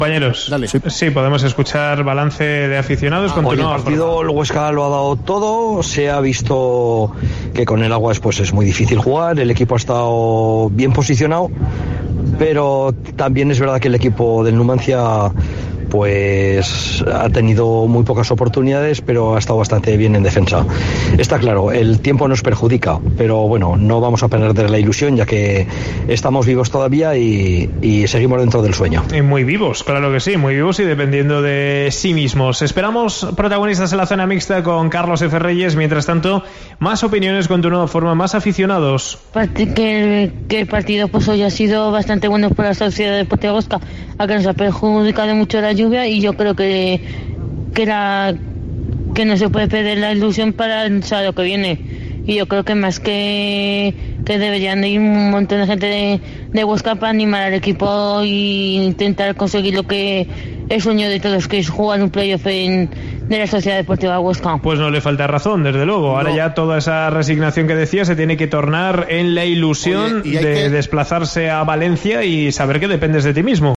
La afición opina tras el Huesca 0-0 Numancia